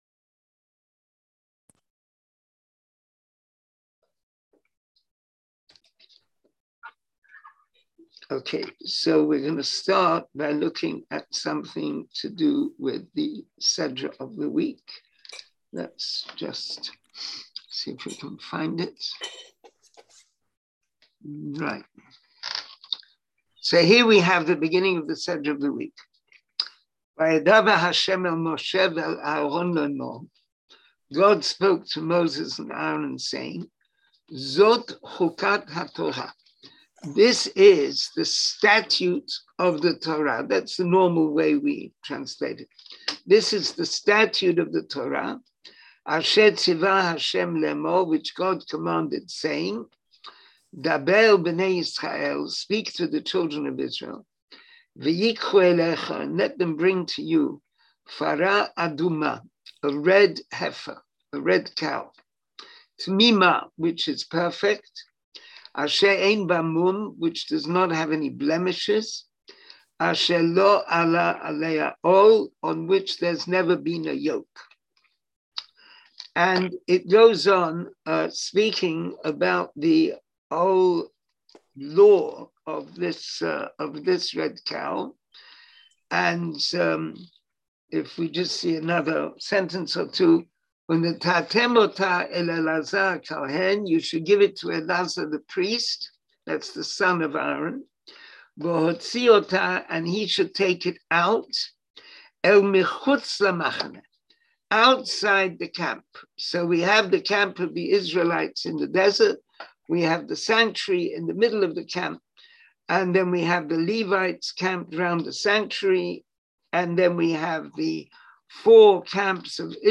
Class audio Listen to the class Watch the video Class material Hebrew Summary of Discourse English Summary of Discourse Join the class?